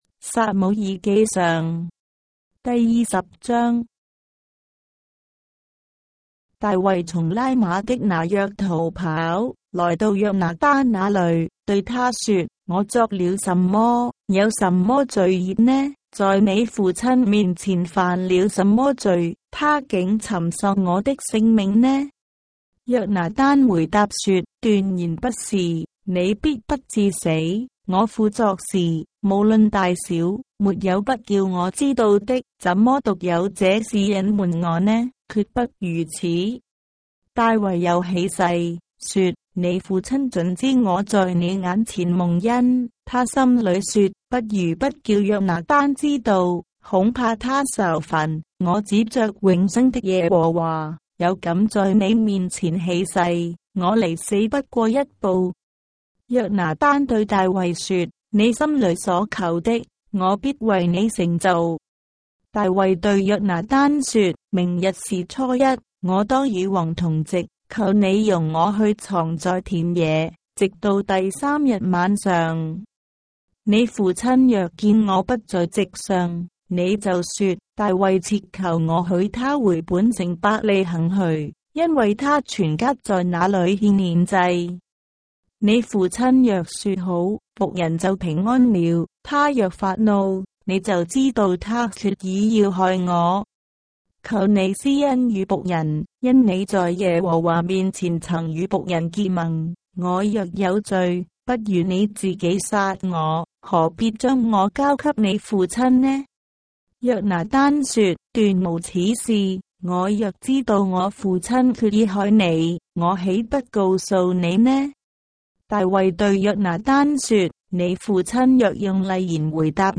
章的聖經在中國的語言，音頻旁白- 1 Samuel, chapter 20 of the Holy Bible in Traditional Chinese